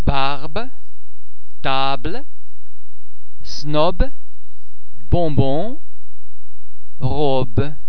The French [b] is normally pronounced [b] as in the English words baby, bar etc.
b - as in
b_barbe.mp3